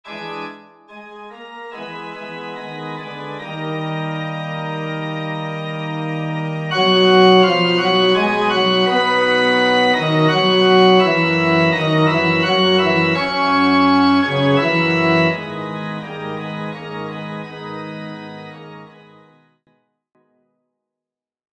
FF:VH_15b Collegium musicum - mužský sbor, FF:HV_15b Collegium musicum - mužský sbor
Utonula_solo.mp3